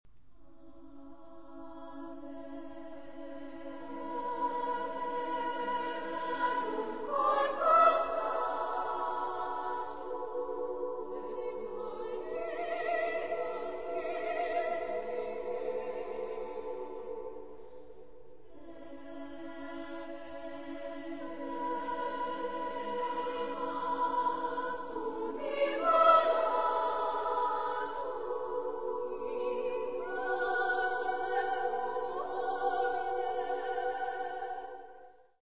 Genre-Style-Forme : Sacré ; Hymne (sacré)
Solistes : Soprano (1) / Alto (1)  (2 soliste(s))
Tonalité : la majeur